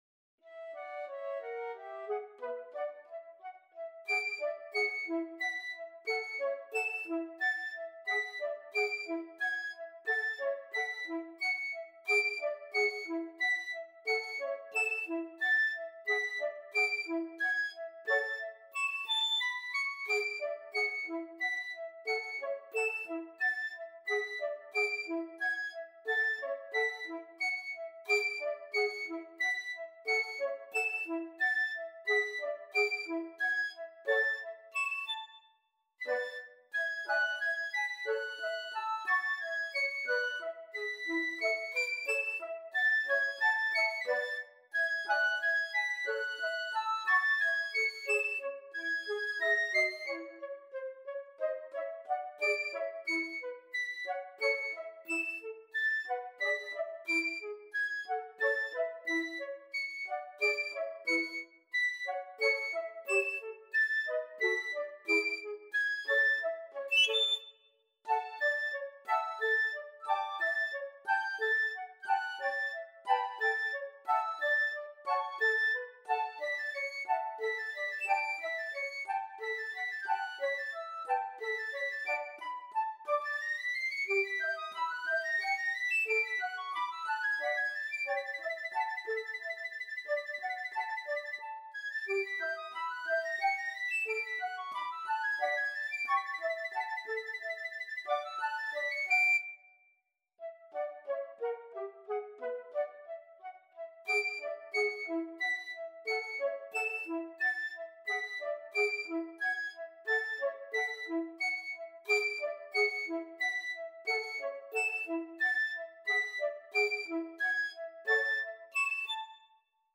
piccolo and flutes